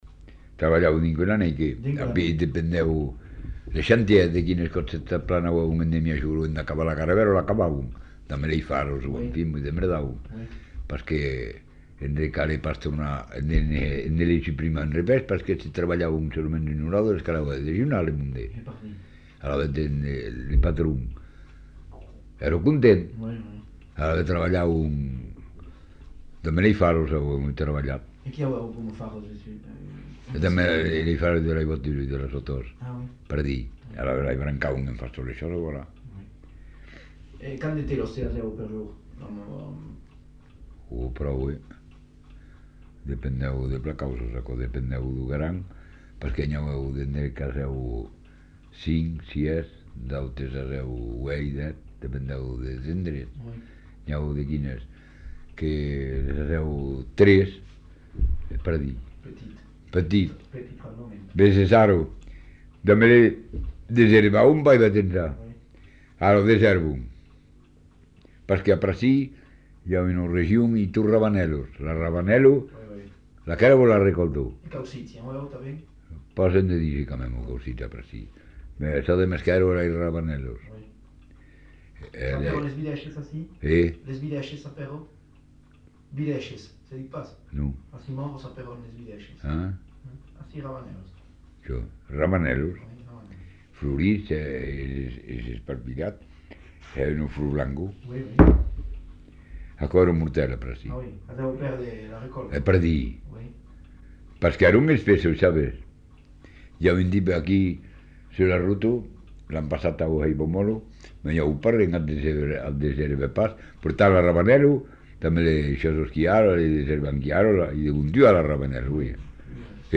Lieu : Saint-Thomas
Genre : récit de vie